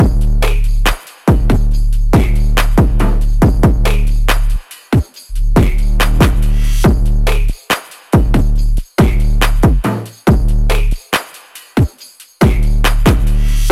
Busted Phonk Type Drums - 140 C.ogg
Hard punchy kick sample for Memphis Phonk/ Hip Hop and Trap like sound.